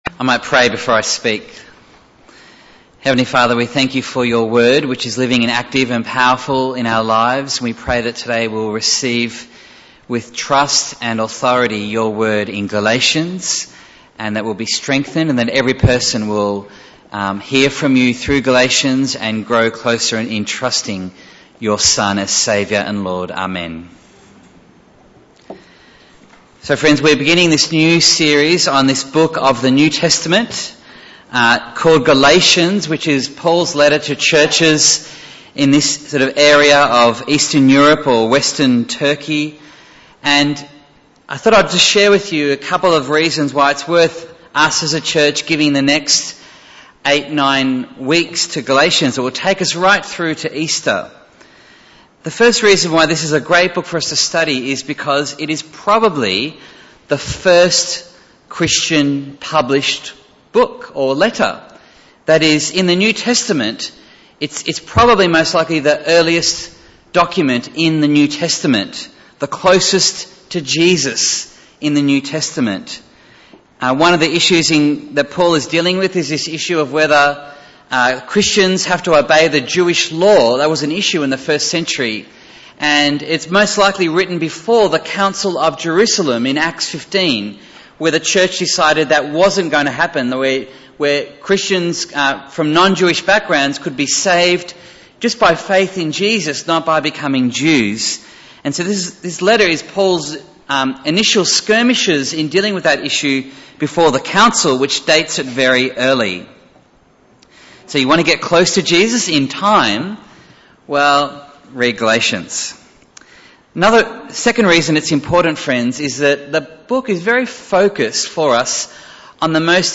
Bible Text: Galatians 1:1-9 | Preacher